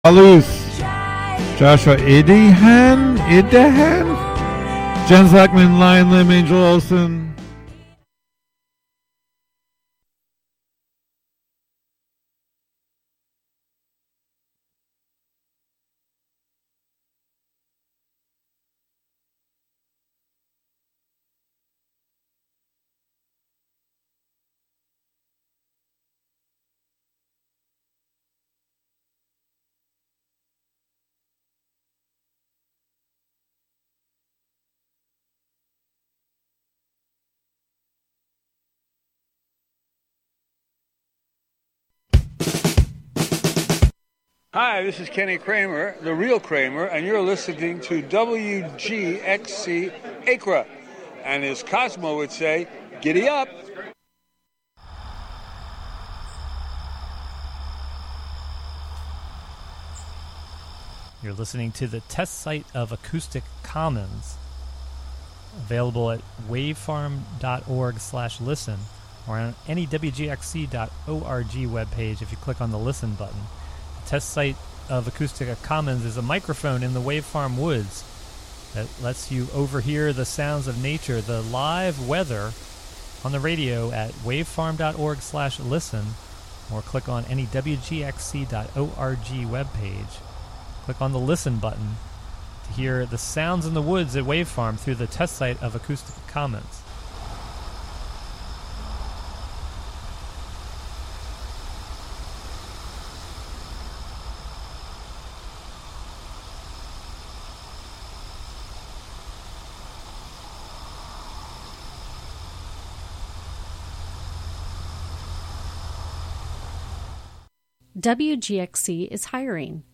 Tune in for radio art, radio theatre, experiments, live shows, and more.